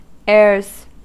Ääntäminen
Ääntäminen US UK : IPA : /eə(ɹ)z/ US : IPA : /eɹz/ Haettu sana löytyi näillä lähdekielillä: englanti Airs on sanan air monikko.